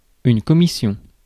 Ääntäminen
US : IPA : [pɚ.ˈsɛn.tɪdʒ]